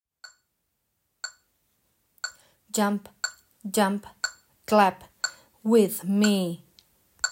Rhythm and words
They contain phrases pronounced imitating the scores on the second column.